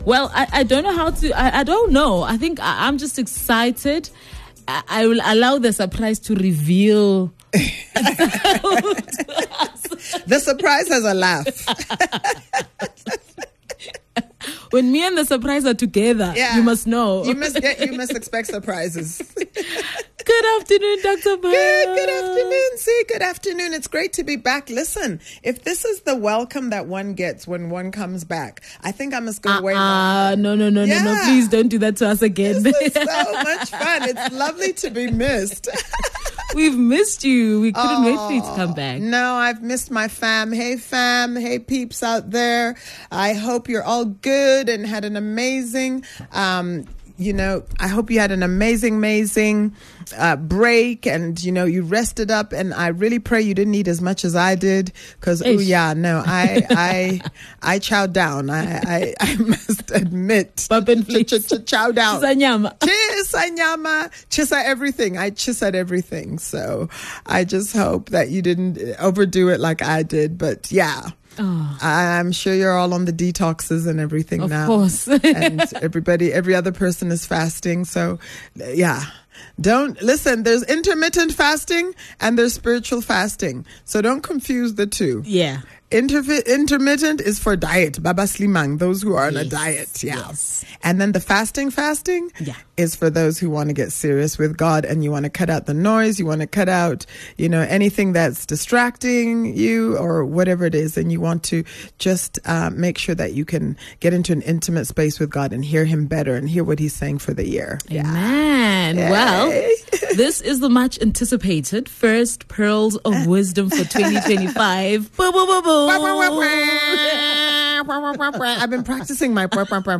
On this channel, we share some of our presenters most valuable and encouraging links from their shows, interviews with guests and other other valuable content.